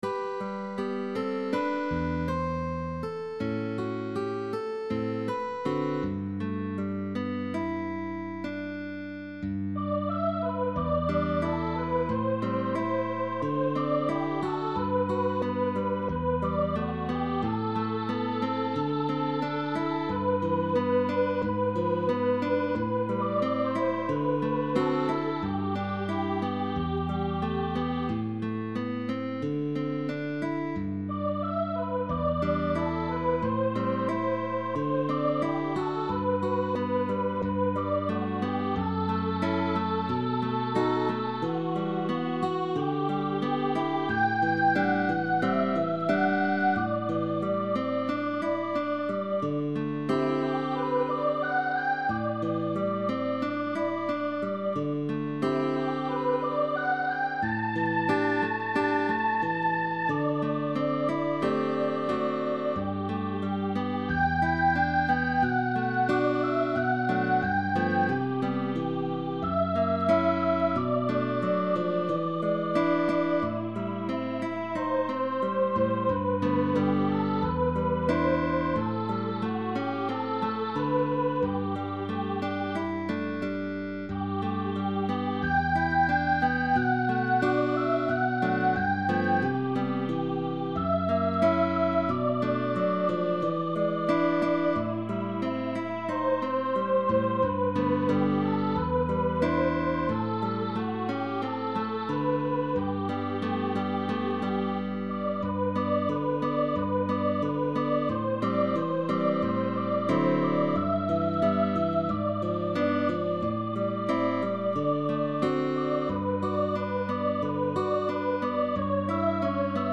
Arrangement for voice with guitar accompaniment.
Pop music